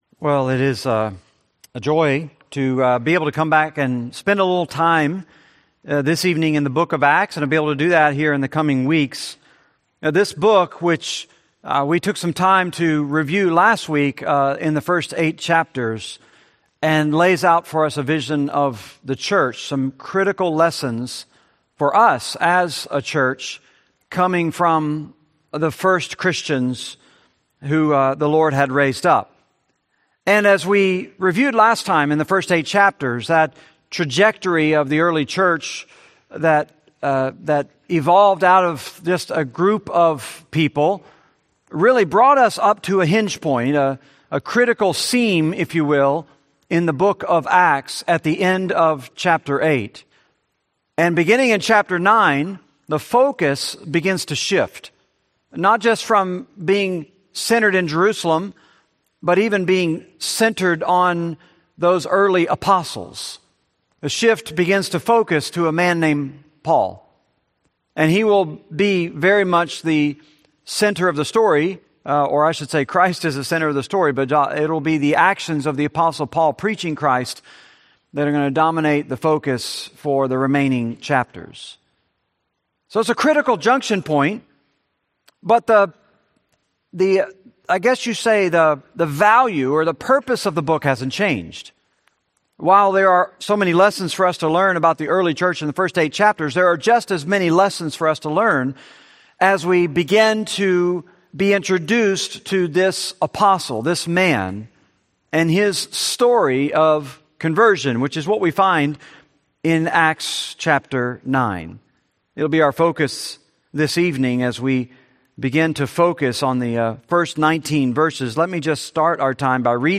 Series: Benediction Evening Service, Sunday Sermons